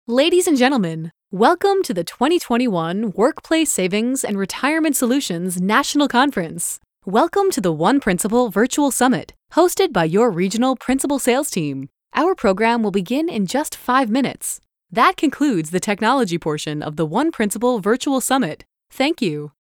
Live Announce - Female Voice of God (VOG)
Pre-recorded or live, in-person voiceover talent for your event.
Awards Ceremony